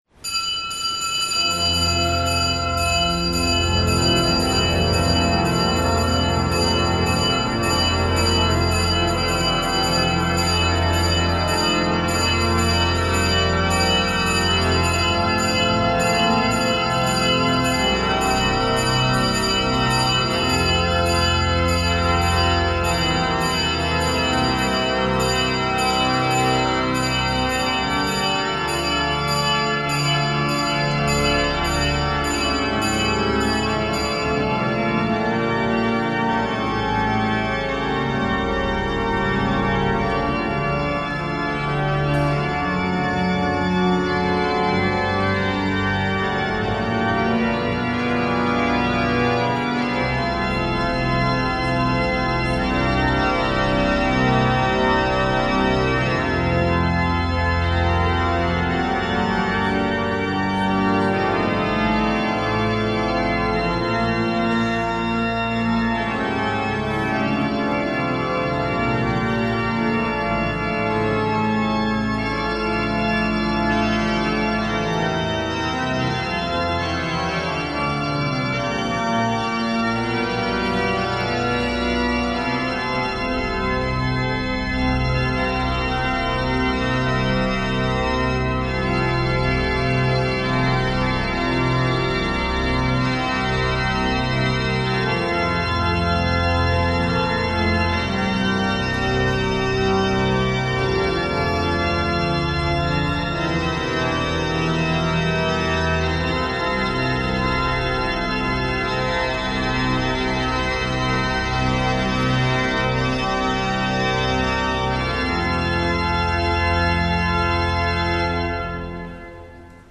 Sv. maša iz stolne cerkve sv. Janeza Krstnika v Mariboru dne 23. 11.
Sv. mašo za pokojnega škofa msgr. Jožefa Smeja je daroval mariborski nadškof msgr. Alojzij Cvikl. Pele so šolske sestre.